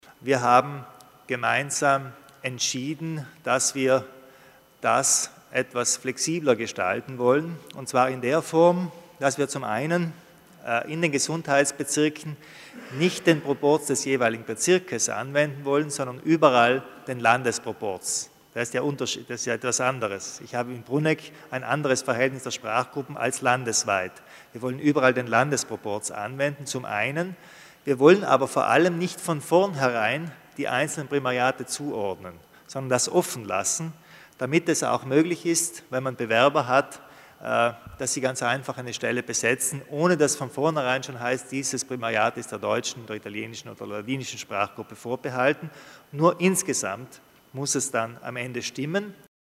Landeshauptmann Kompatscher erläutert die Neuheiten bei der Vergabe von Primariaten